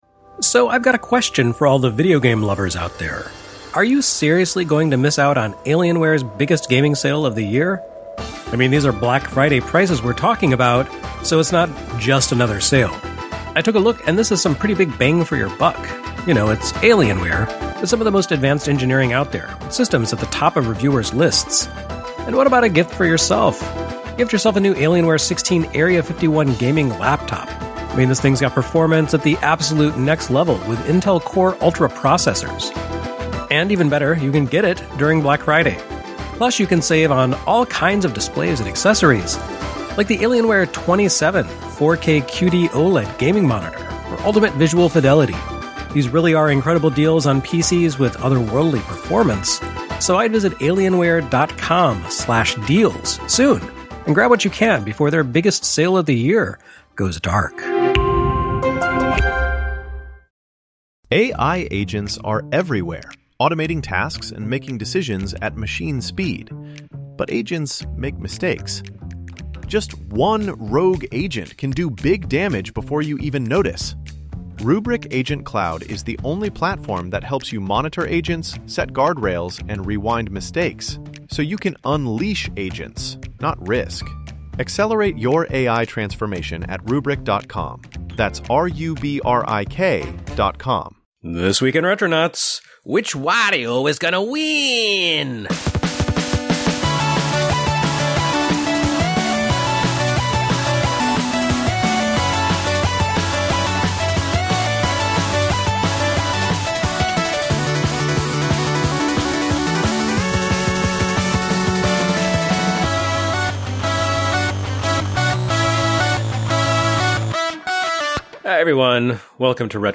Thanks to Wario's evil influence, there's a bit of profanity mixed into the conversation, but there's also plenty of laughs.